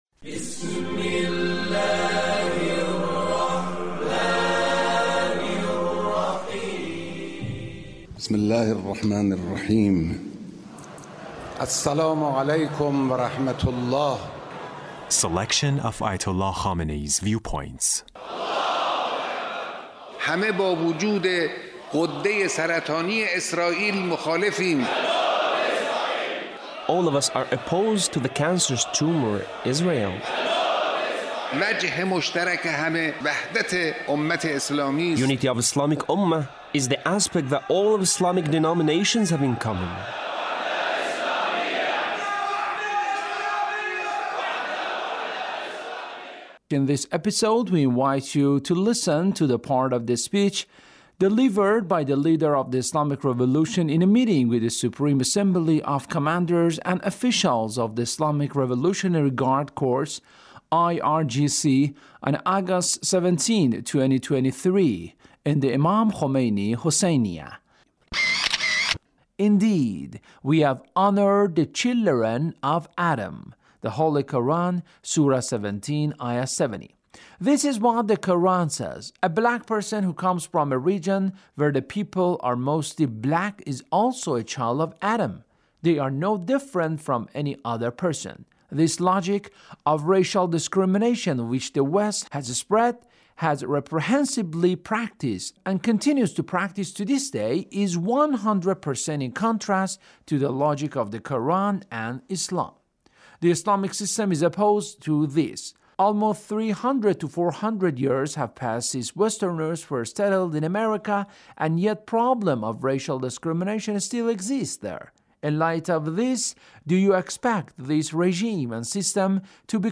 Leader's Speech (1821)
Leader's Speech in a meeting with Revolution in a meeting with the Supreme Assembly of Commanders and Officials of the Islamic Revolutionary Guard Corps (...